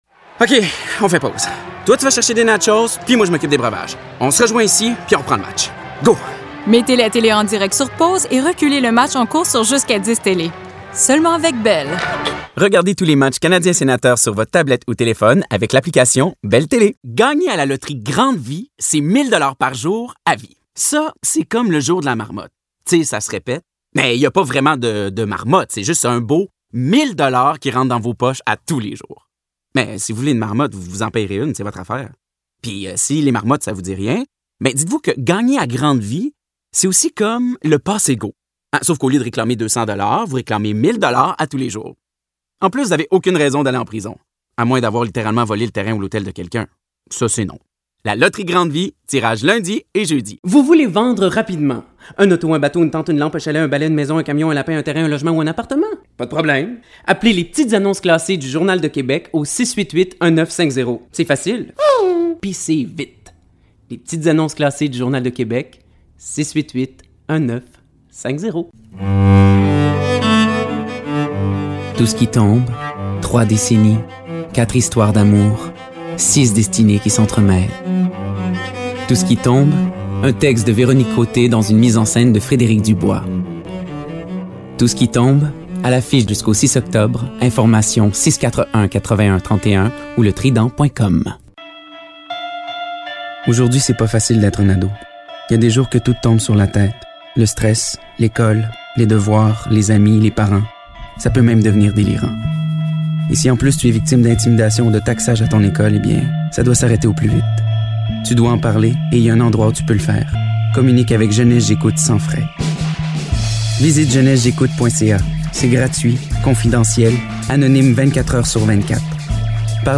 Commercial Demo - FR